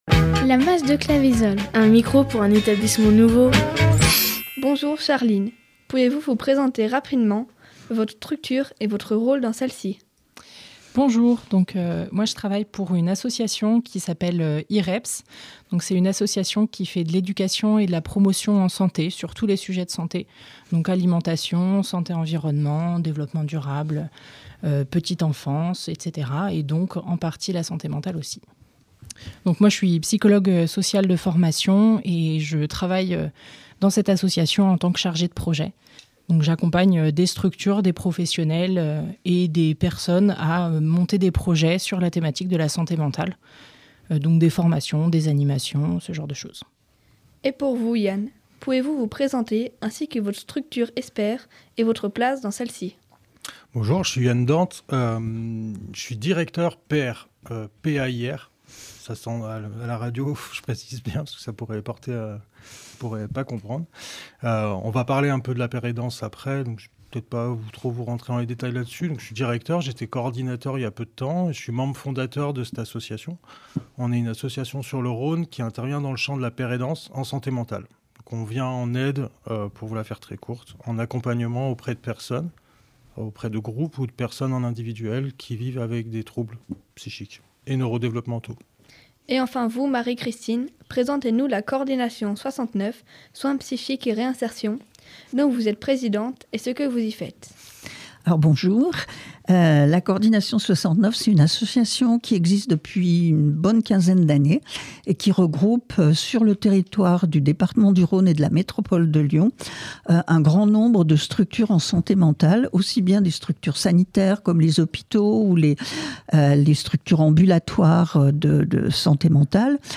Mas de Claveisolle – Interview Complet 1
Mas-de-Claveisolle-Interview-Complet-1.mp3